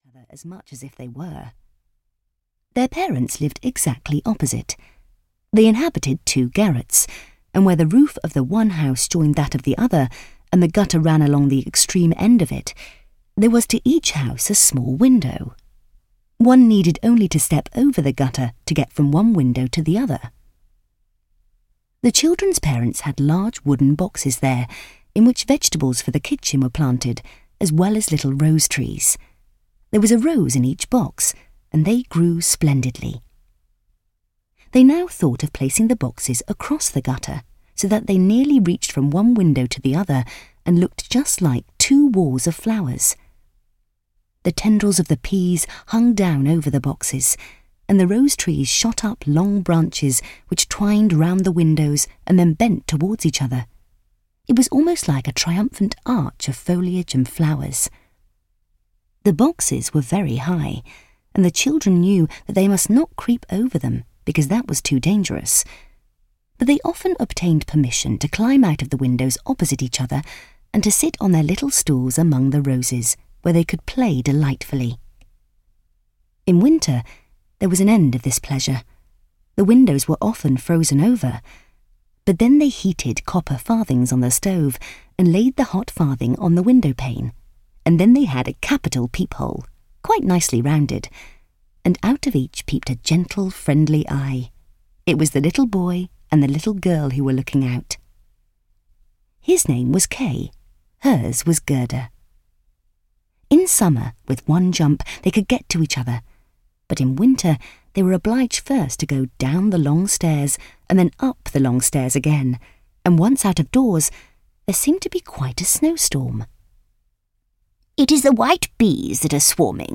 The Snow Queen and Other Stories (EN) audiokniha
Ukázka z knihy